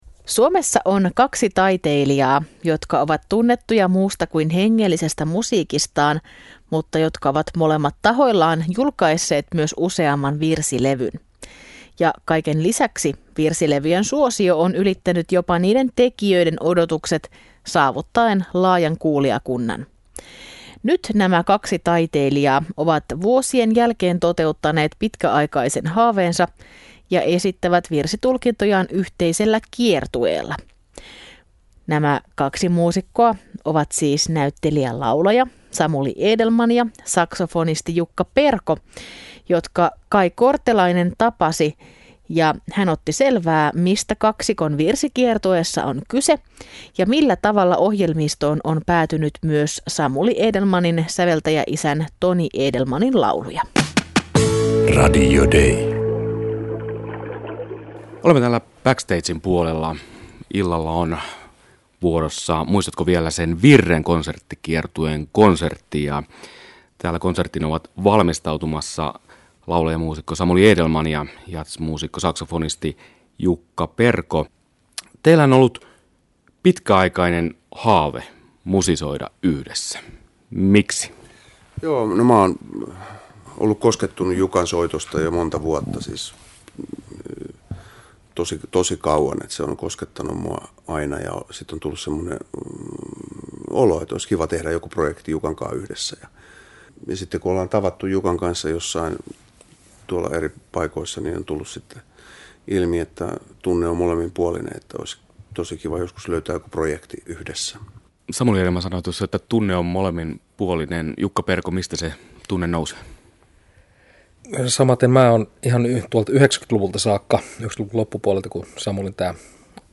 Kaksikon yhteisiä muistoja oli jakamassa myös saksofonisti Jukka Perko.
Haastattelussa Samuli Edelmann ja Jukka Perko